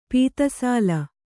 ♪ pīta sāla